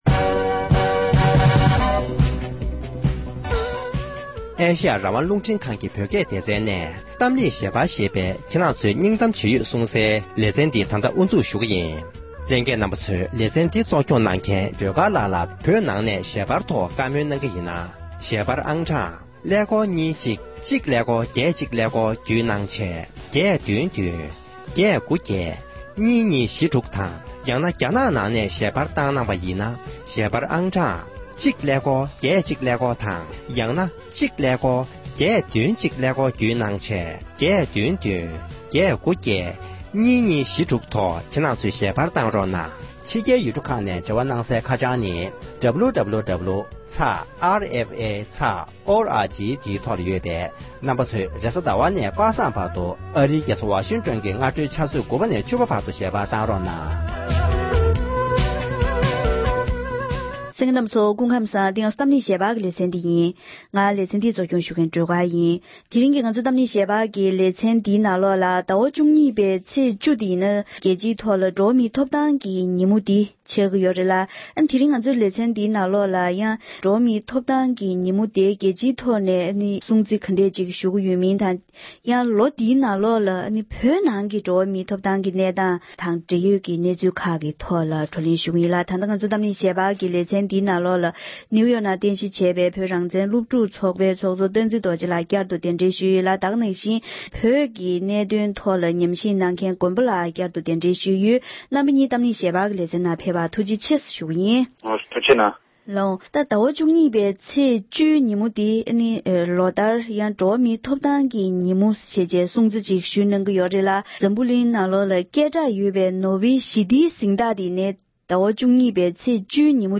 འགྲོ་བ་མིའི་ཐོབ་ཐང་ཉིན་མོ་དང་སྟབས་བསྟུན་ནས་སྤྱི་ལོ་༢༠༡༡ལོའི་བོད་ནང་གི་འགྲོ་བ་མིའི་ཐོབ་ཐང་གི་གནས་སྟངས་ཐད་བགྲོ་གླེང༌།